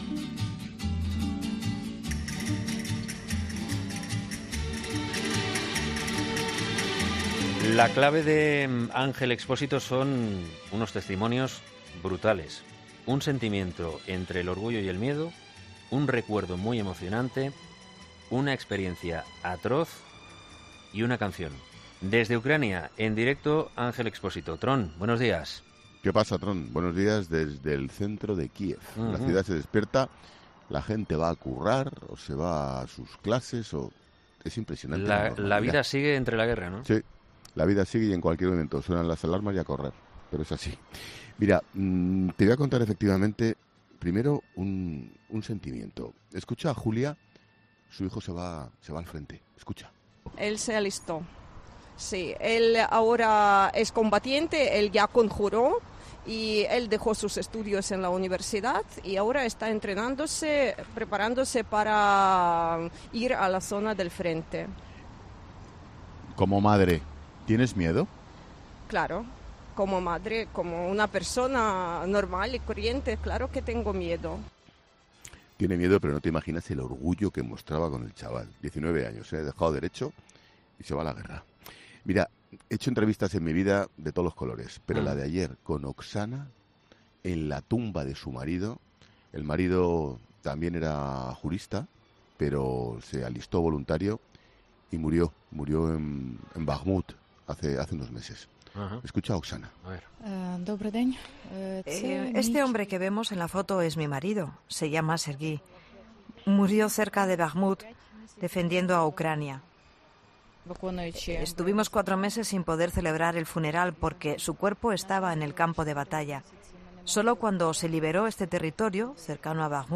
El director de La Linterna sigue narrando, desde la capital ucraniana, los últimos giros de la guerra con testimonios intensos y, algunos, desgarradores, entre orgullo y miedo